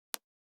448リップクリーム,口紅,ふたを開ける,
効果音